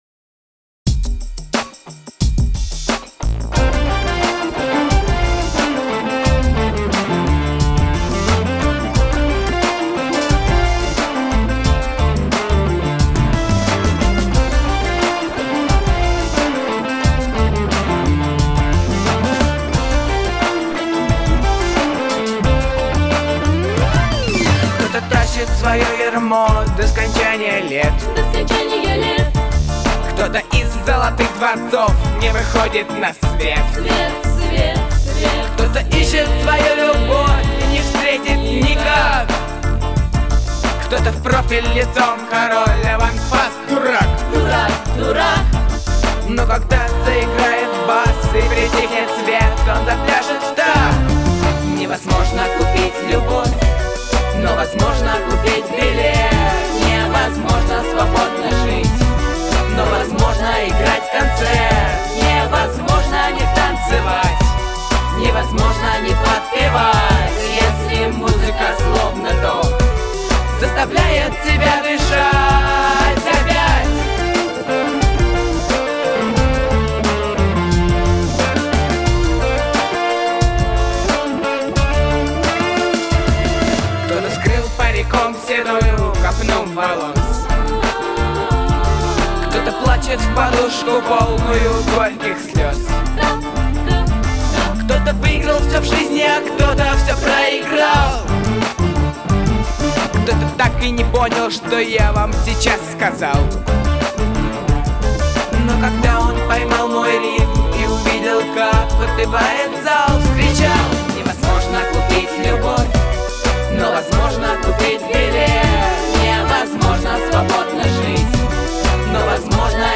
Записано в студии